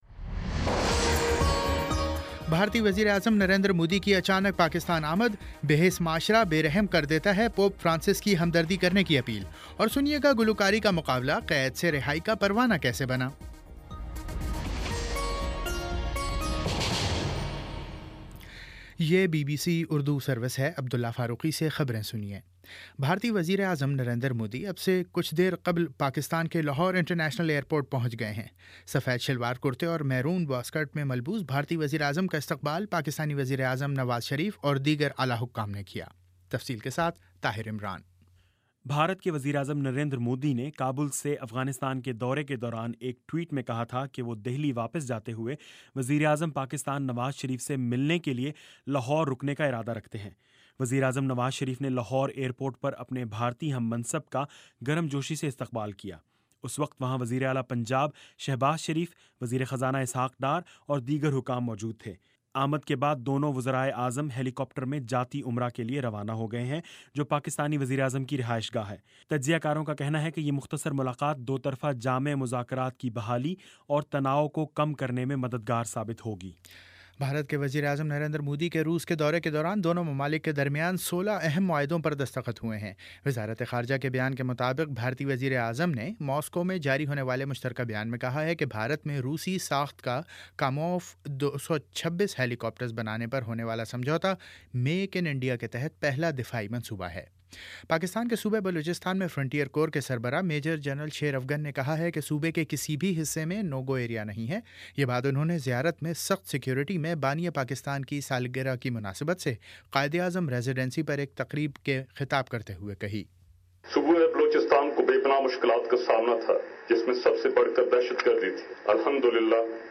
دسمبر 25 : شام پانچ بجے کا نیوز بُلیٹن